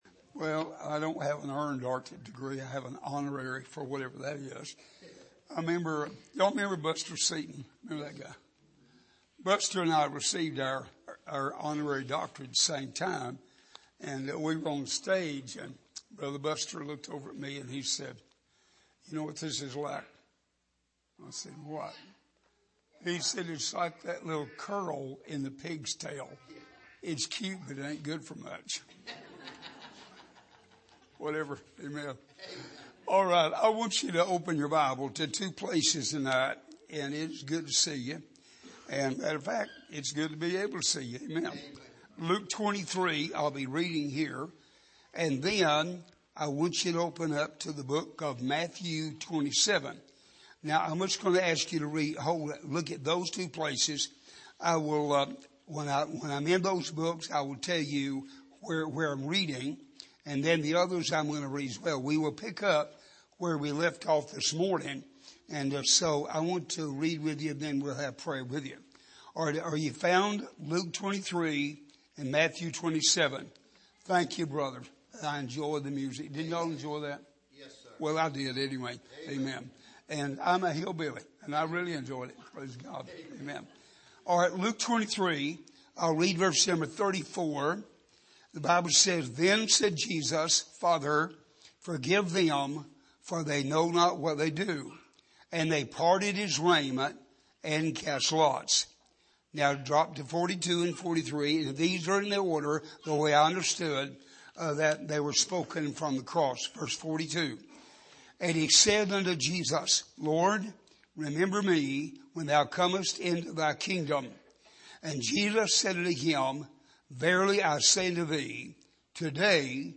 Here is an archive of messages preached at the Island Ford Baptist Church.